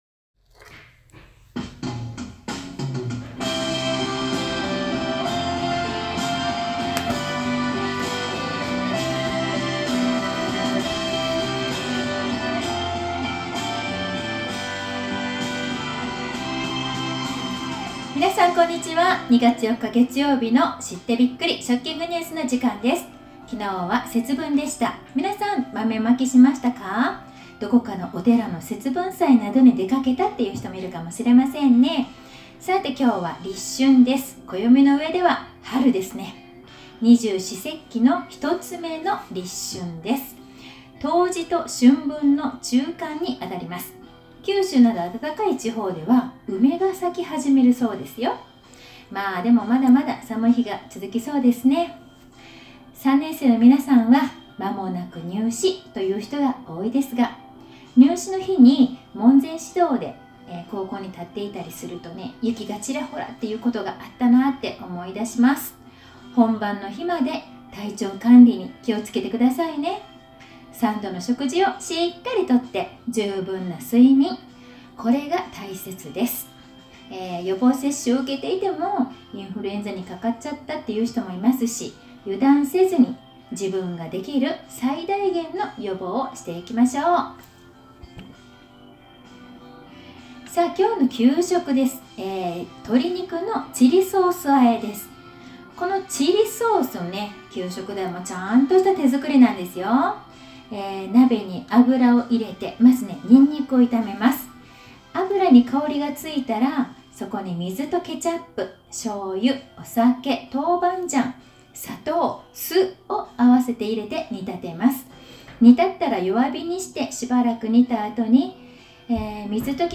２月４日月曜日の給食＆食育放送です。